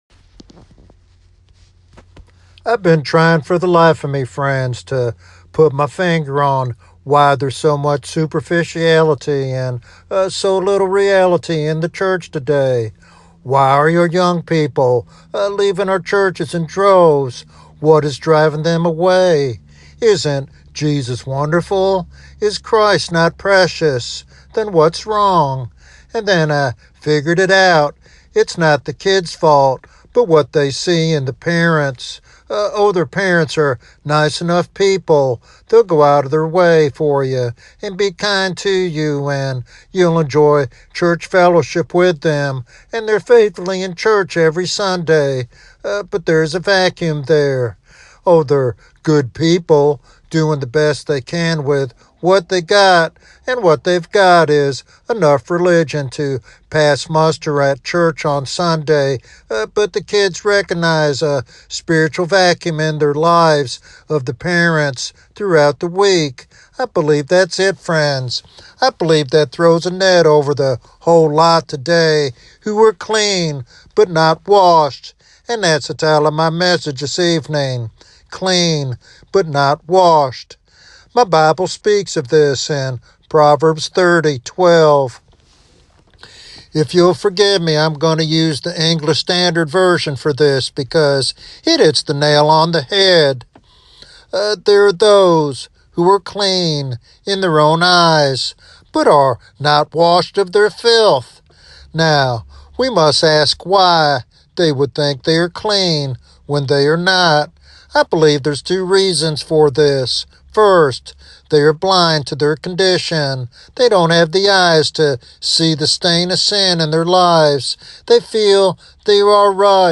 This sermon is a heartfelt appeal for authenticity in the Christian life.